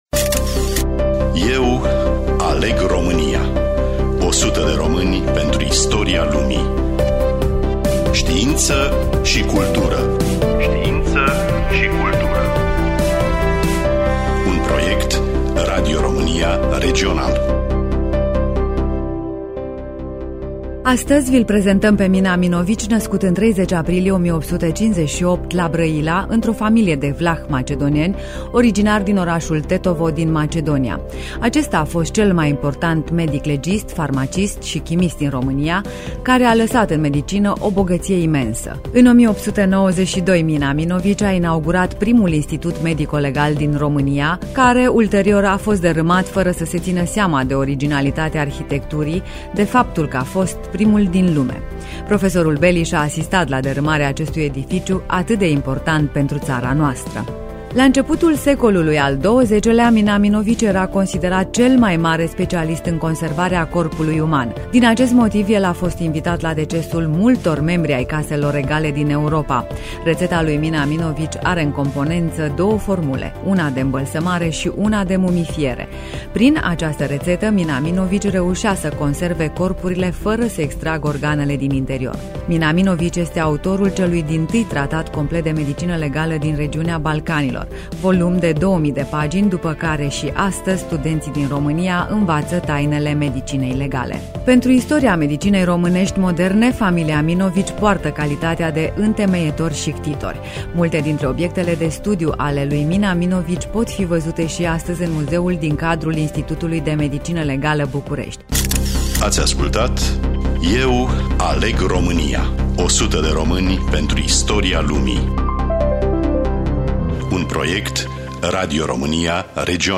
Studioul: Radio România Reşiţa